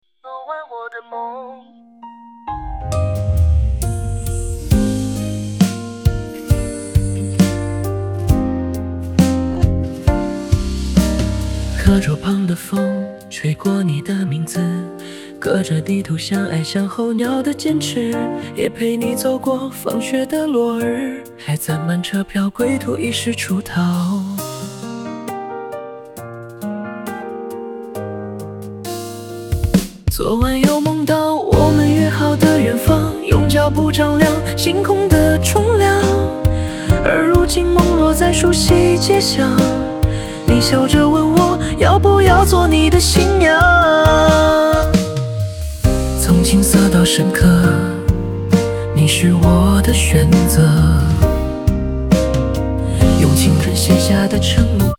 —— 女生告白版
人工智能生成式歌曲